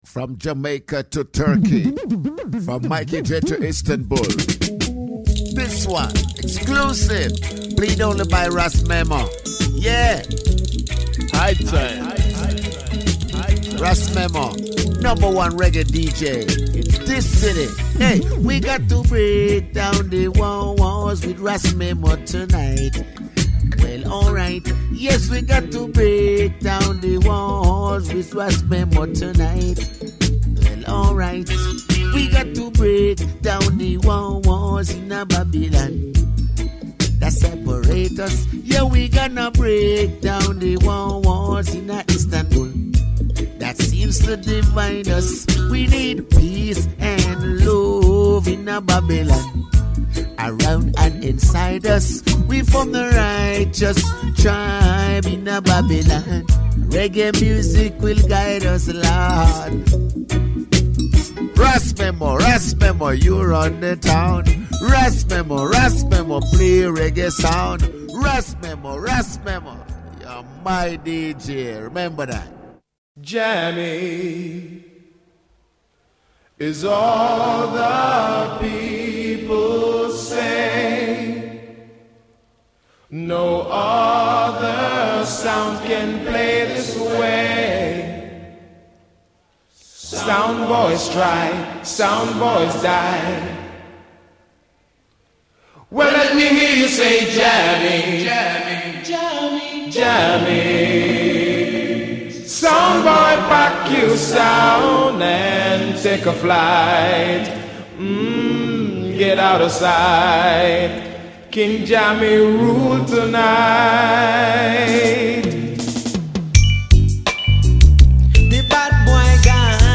Radio Show